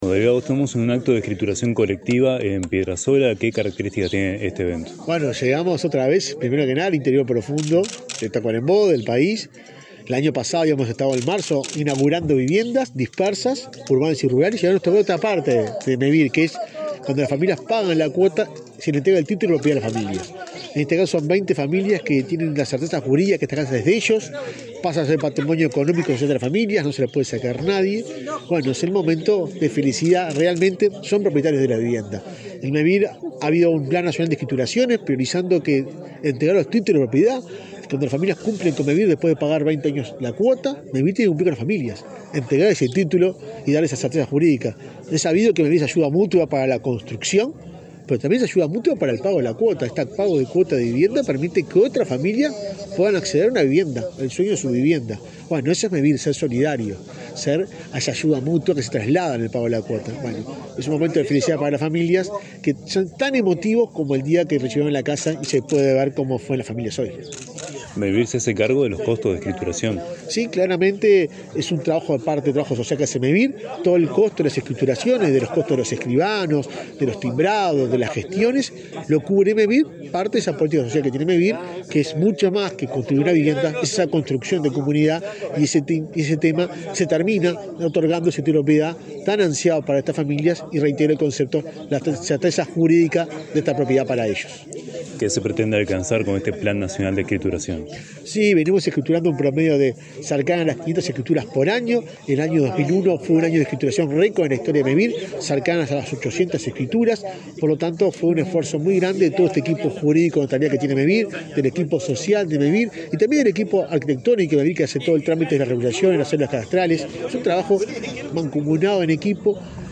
Declaraciones del presidente de Mevir, Juan Pablo Delgado
Declaraciones del presidente de Mevir, Juan Pablo Delgado 22/06/2023 Compartir Facebook X Copiar enlace WhatsApp LinkedIn Este jueves 22, Mevir realizó un acto de escrituras colectivas de viviendas para 24 familias en Piedra Sola, departamento de Tacuarembó. Luego el presidente de al entidad, Juan Pablo Delgado, dialogó con la prensa.